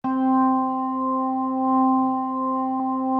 B3LESLIE C 5.wav